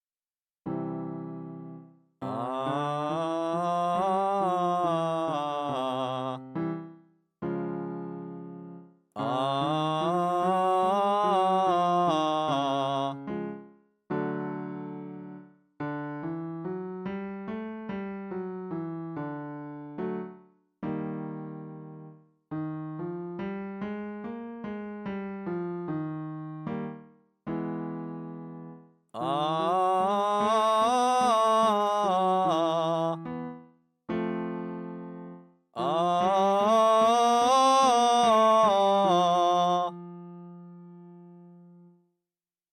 充実した地声／チェストボイス／胸声の練習方法
音量注意！
1. 発音は「ア」
2. 音域は～C4
3. 音量は適度に大きく
practice-chestvoice-02.mp3